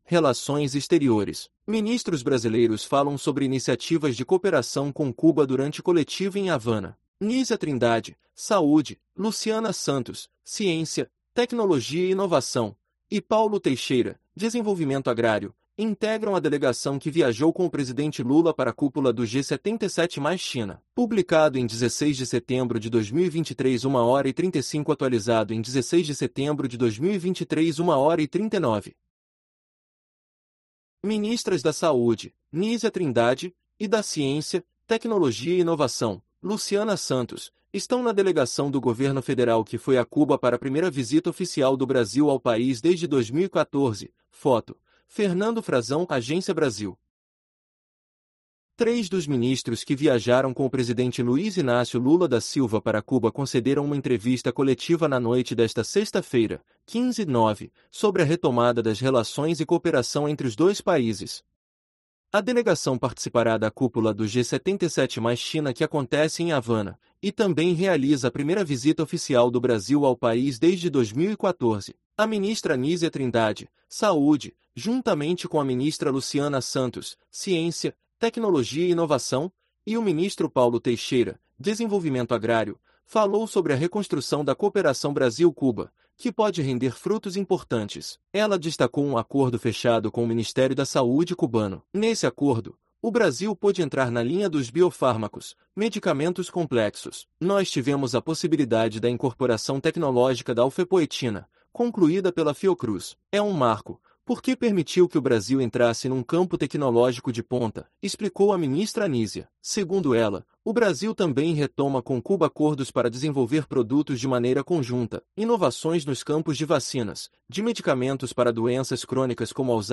Ministros brasileiros falam sobre iniciativas de cooperação com Cuba durante coletiva em Havana
Nísia Trindade (Saúde), Luciana Santos (Ciência, Tecnologia e Inovação) e Paulo Teixeira (Desenvolvimento Agrário) integram a delegação que viajou com o presidente Lula para a cúpula do G77 + China